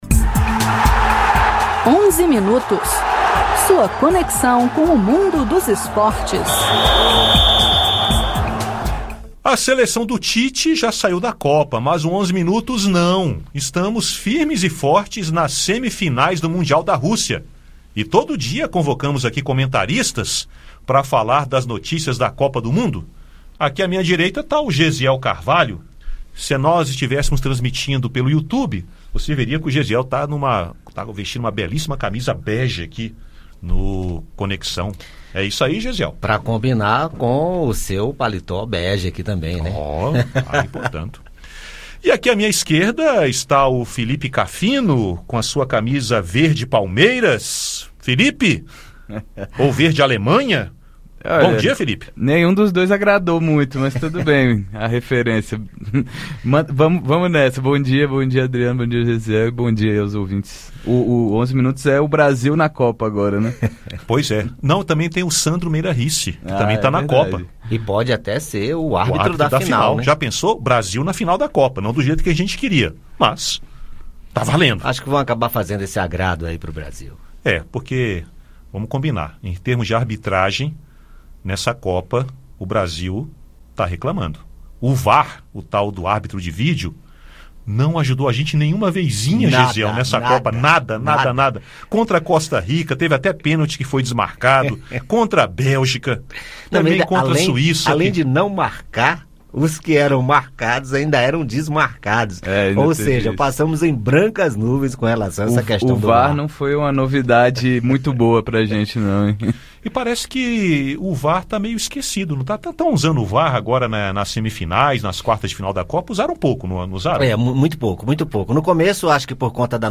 Nesta quarta-feira tem semifinal da Copa da Rússia: Croácia contra a Inglaterra. Os jornalistas da Rádio Senado falam das expectativas para o jogo.